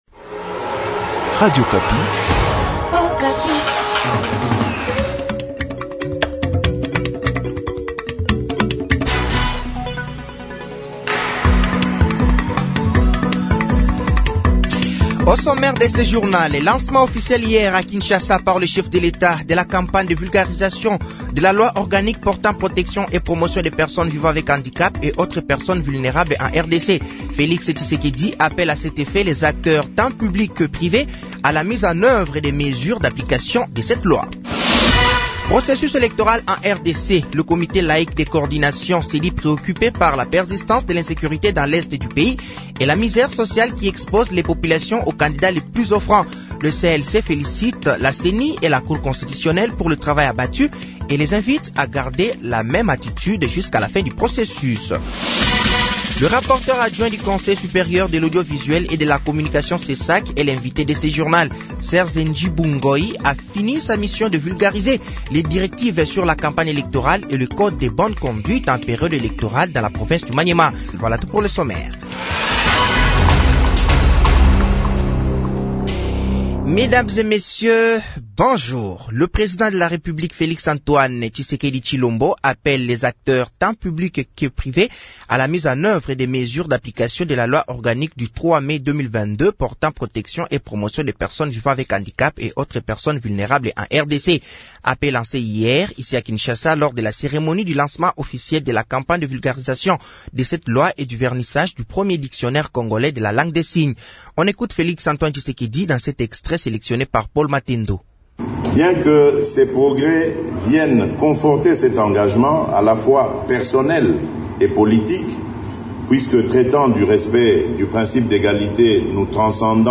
Journal français de 7h de ce dimanche 05 novembre 2023